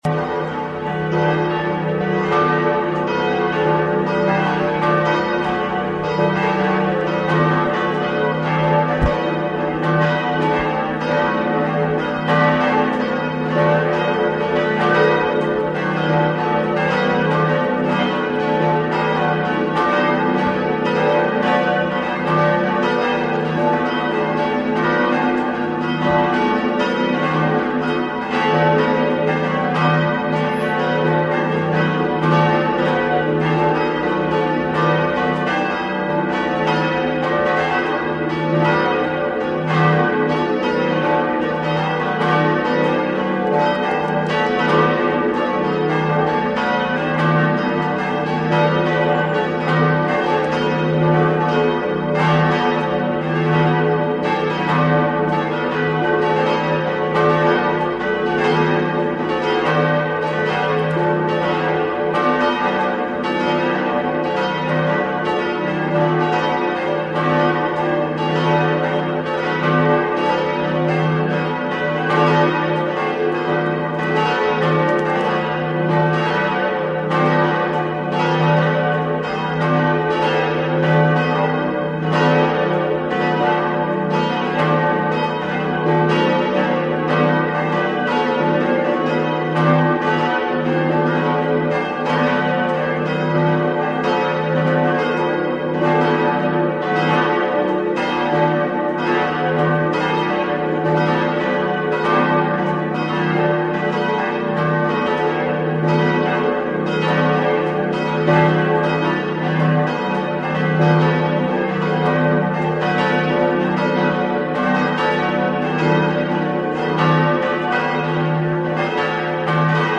😉 Die Aufnahme stammt von unserem Wiesbaden-Dreh.
Glockenlaeuten.MP3